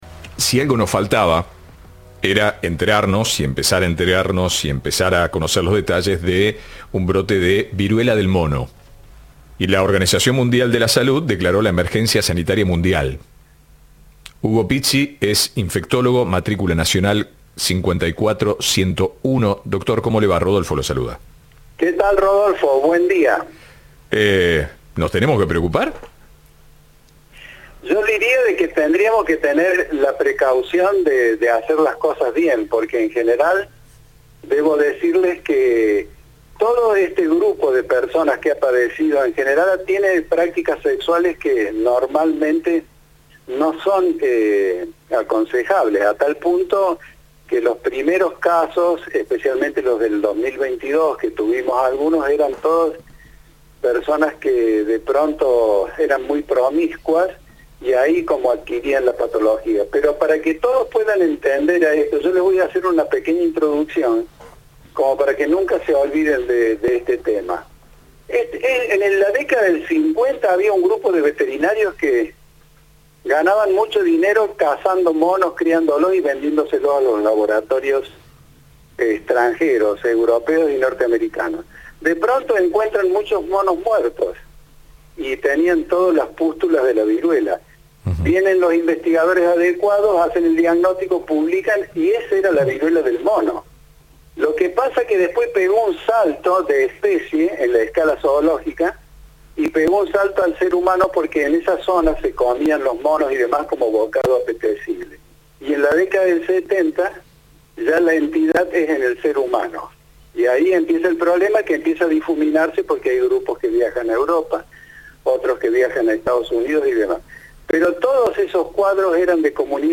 En diálogo con Cadena 3, aseguró que no hay posibilidad de vivir una pandemia, como ocurrió con el Covid-19.